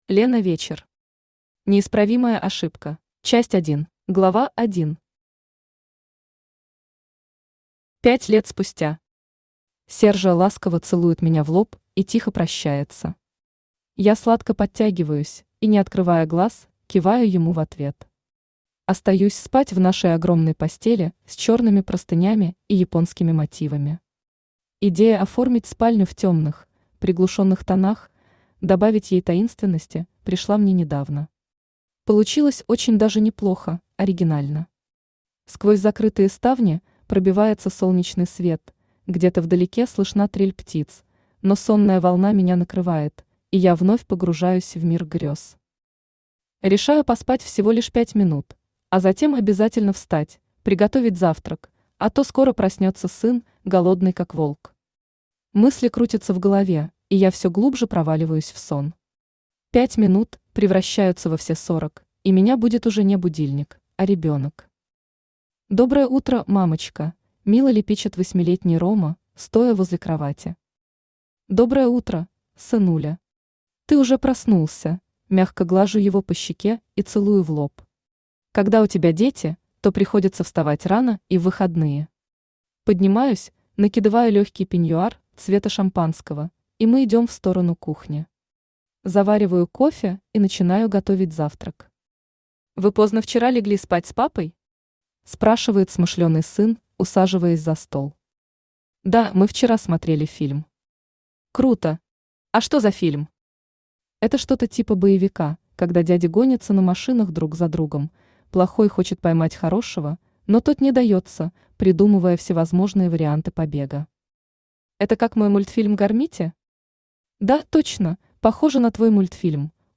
Читает: Авточтец ЛитРес
Аудиокнига «Неисправимая ошибка».